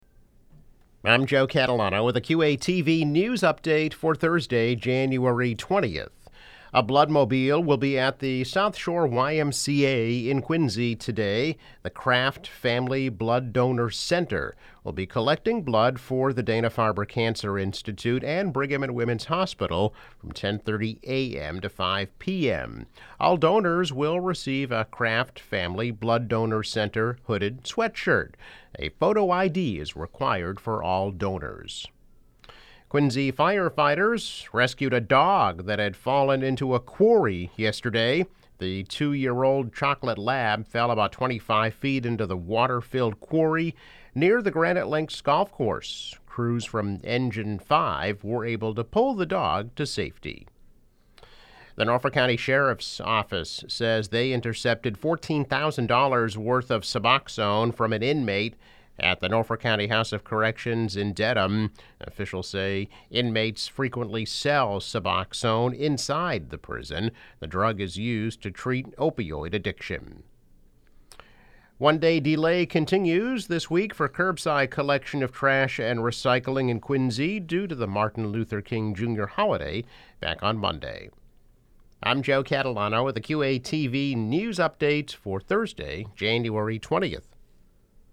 News Update - January 20, 2022